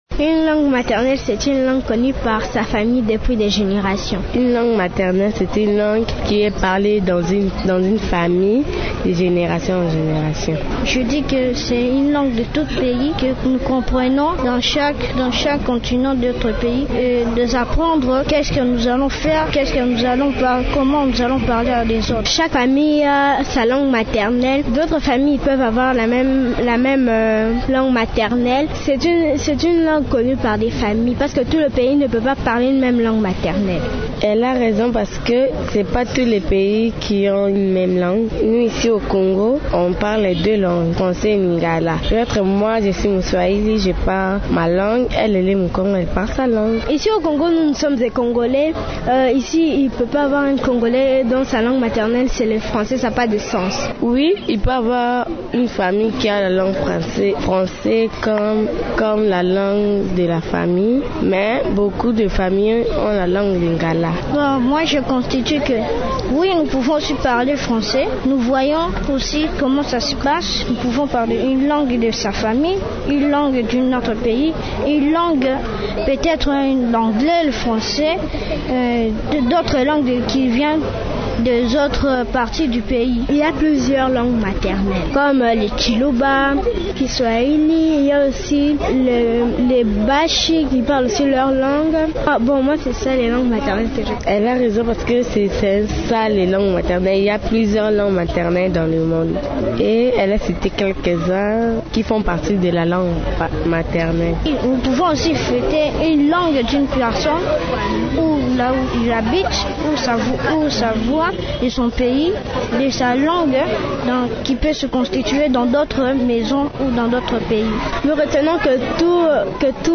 Les enfants parlent de la langue maternelle. Ils la définissent comme la langue parlée dans une famille. Ils sont conscients de ne pas avoir tous la même langue maternelle.